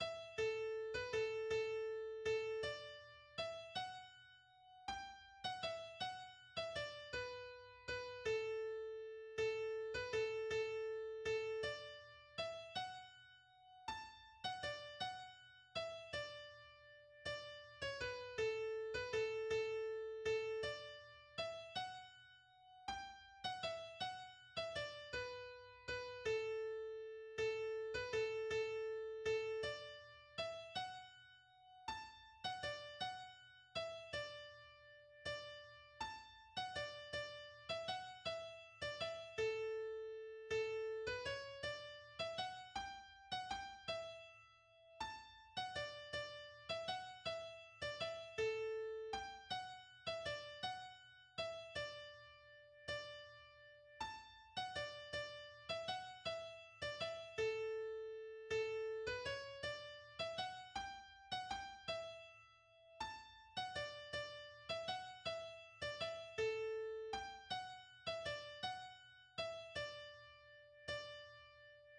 GenreTraditional Folk, Slow march